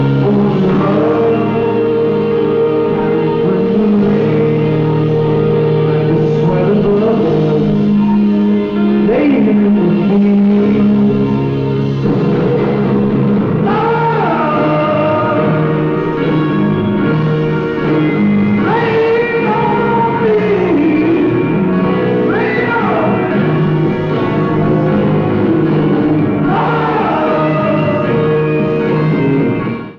Format/Rating/Source: CD - G - Audience
Comments: Horrible audience recording.
Sound Samples (Compression Added):